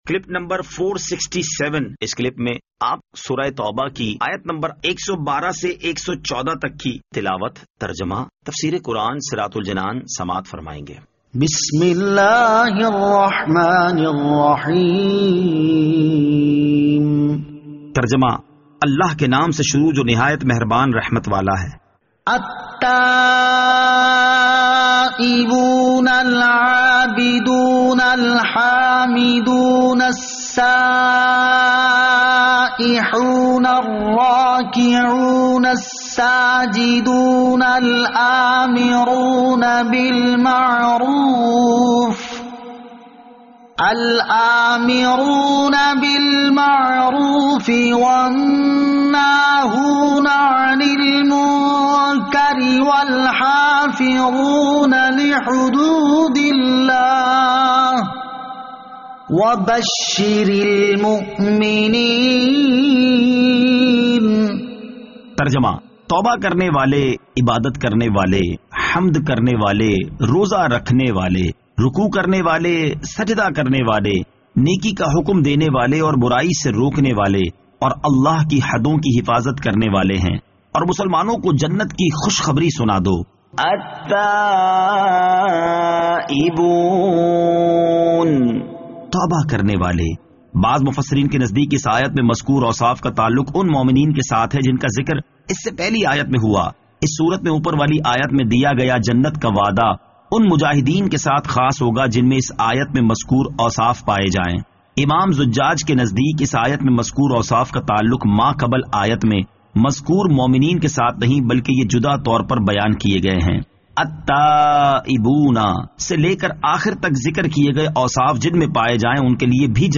Surah At-Tawbah Ayat 112 To 114 Tilawat , Tarjama , Tafseer